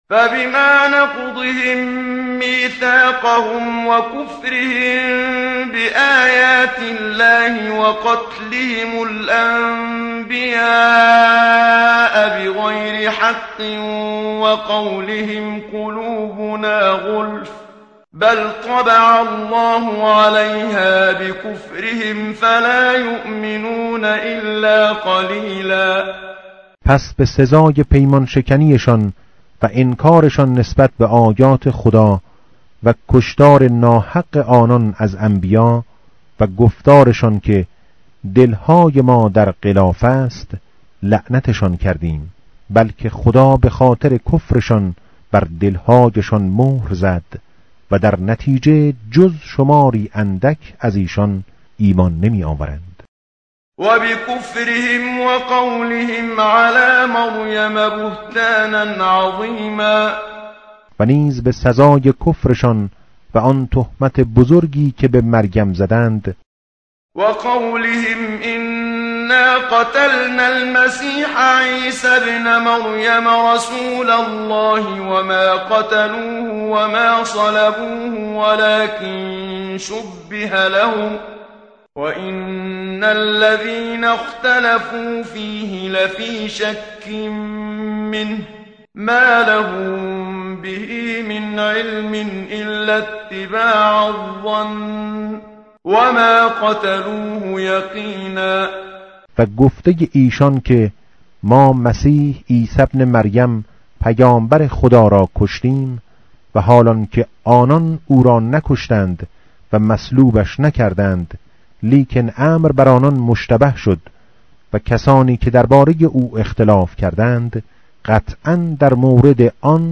متن قرآن همراه باتلاوت قرآن و ترجمه
tartil_menshavi va tarjome_Page_103.mp3